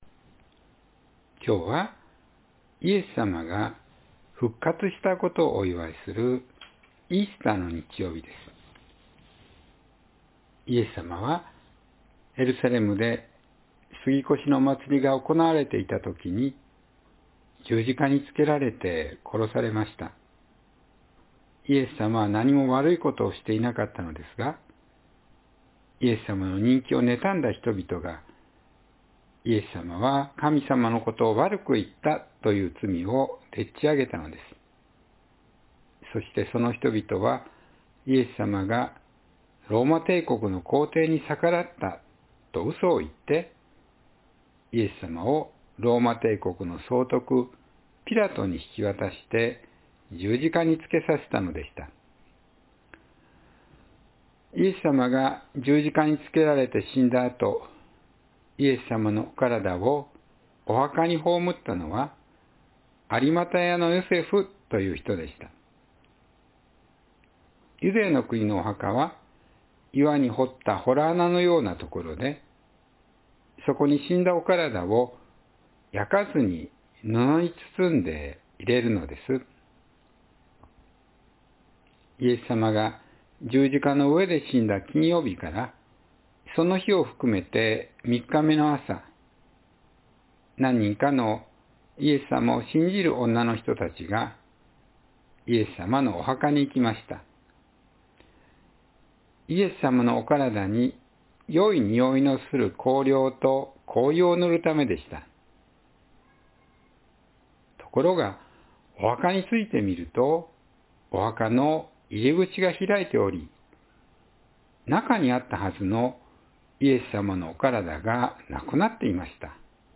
子ども説教